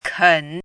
怎么读
kěn
ken3.mp3